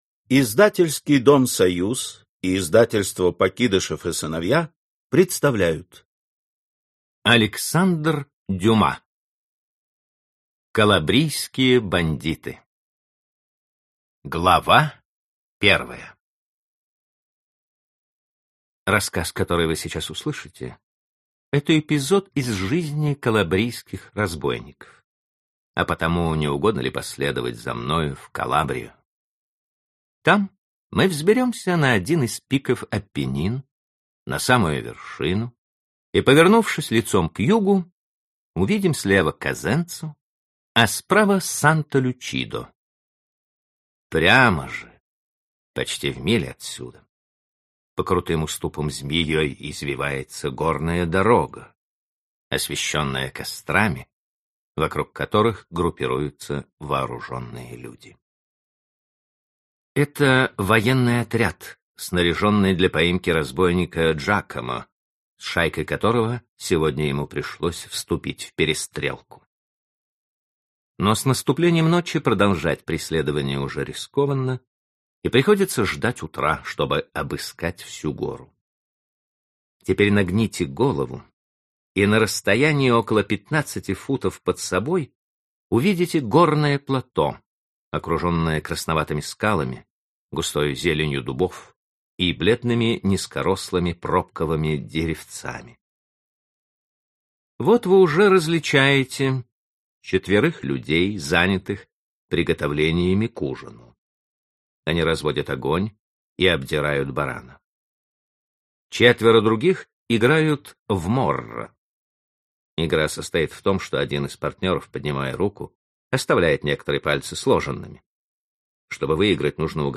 Аудиокнига Калабрийские бандиты | Библиотека аудиокниг